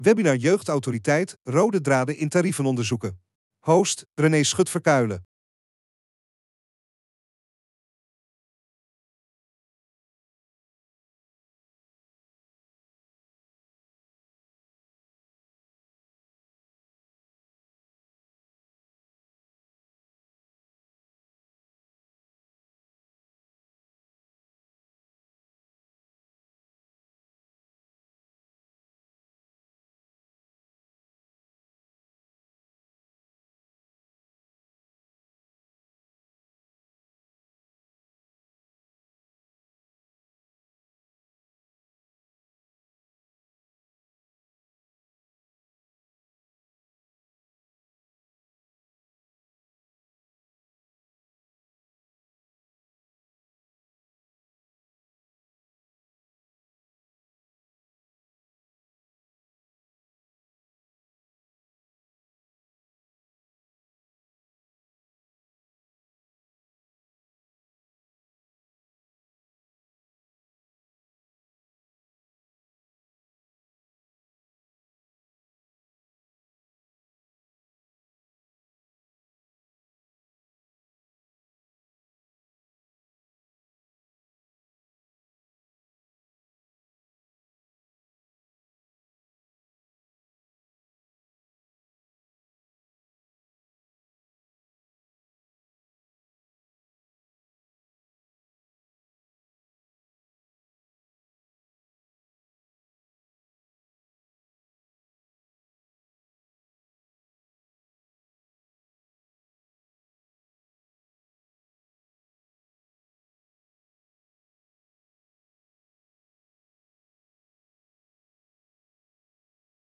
Terugkijklink, presentatie en Q&A van het webinar 'Rode draden in tarievenonderzoeken jeugdhulp' van 23 mei 2024.